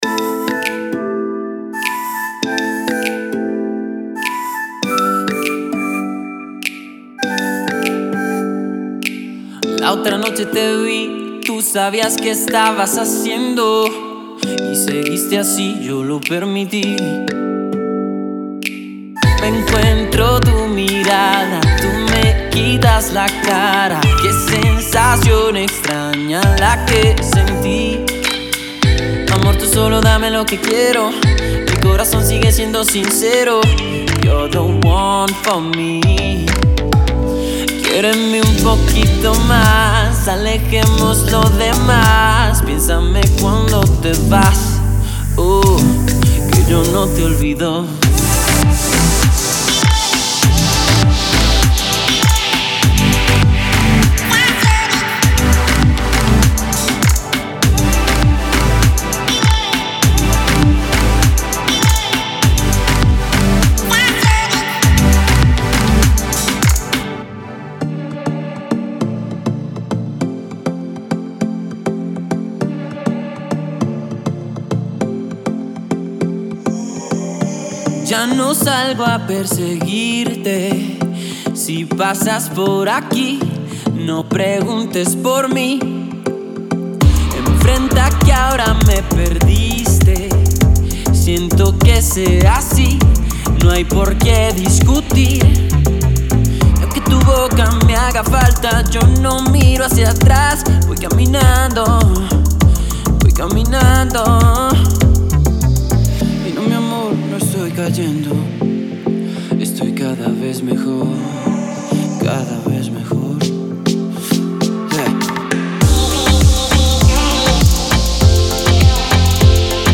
recorded in spanish by a male singer
• 5 Male Vocal Construction Kits
• BPM Range: 95-102 BPM